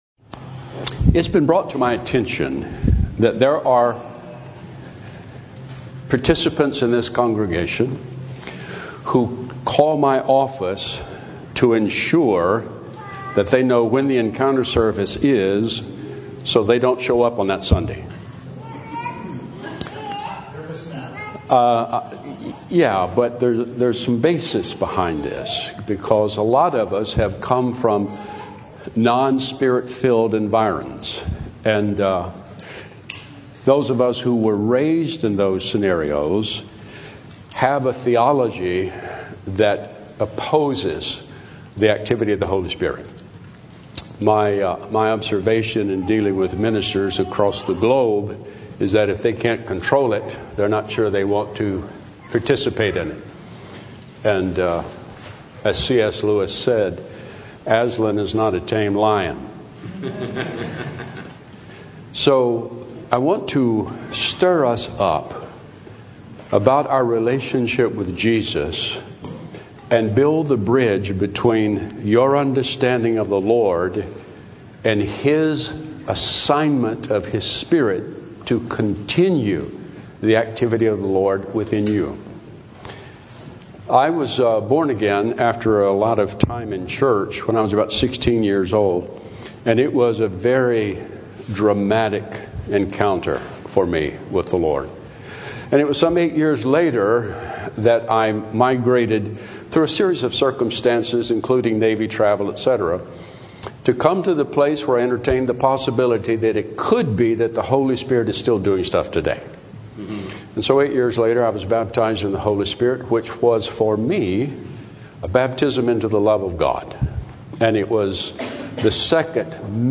Encounter Service: A Challenge